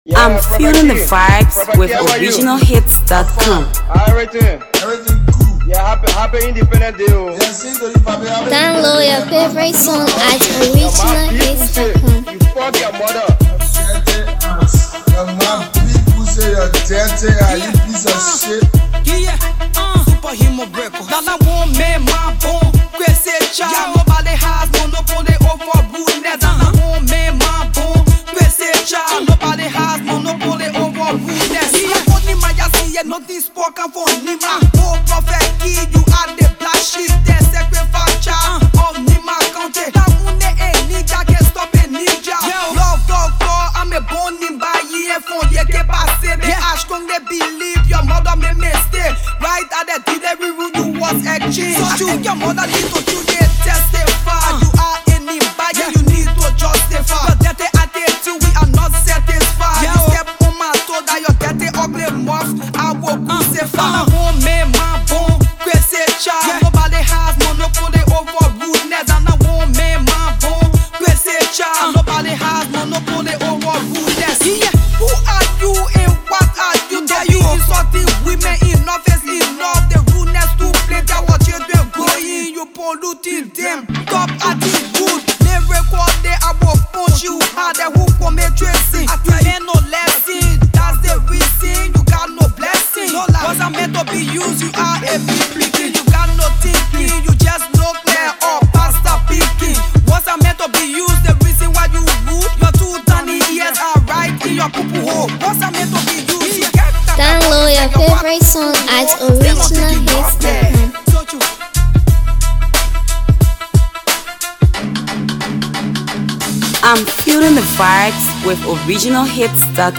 songstress
Spitting real hardcore Barz.